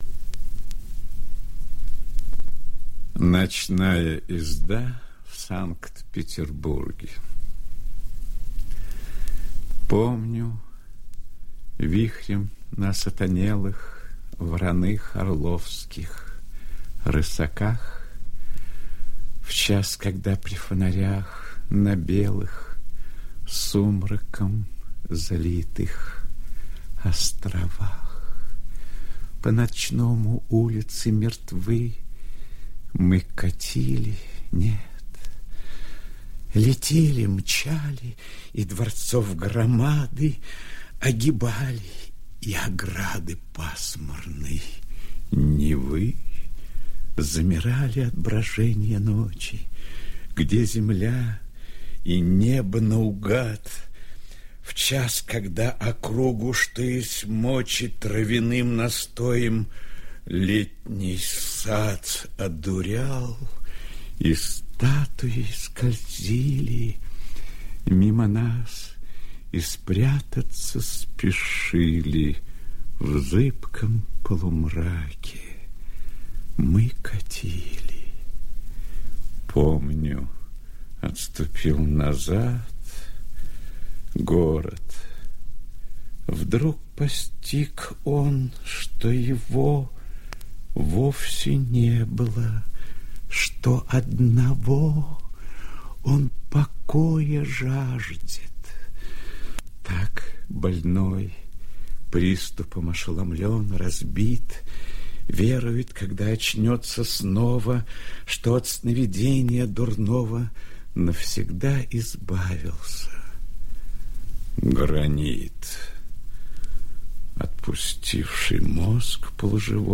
1. «Рильке – Ночная езда( Санкт-Петербург) читает И.Смоктуновский» /
Nochnaya-ezda-Sankt-Peterburg-chitaet-I.Smoktunovskij-stih-club-ru.mp3